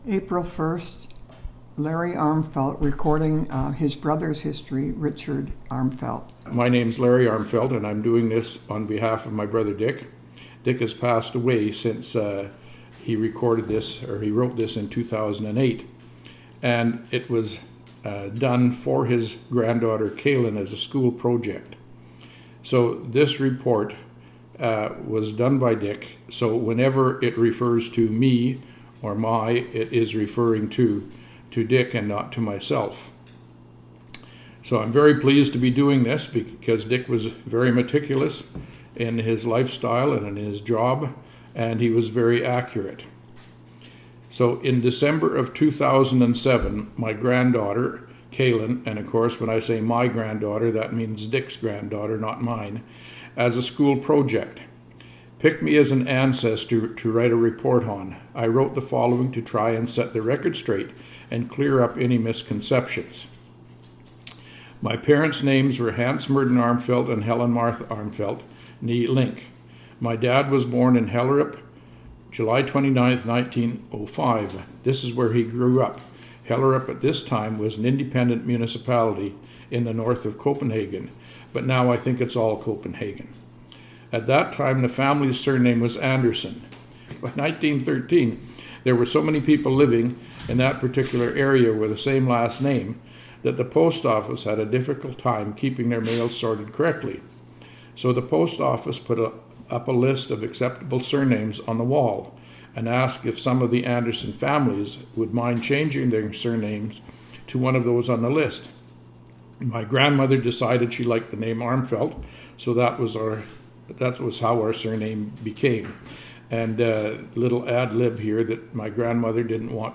Audio interview, transcript of audio interview, booklet 'Some Biographical Reminiscing' and obituary,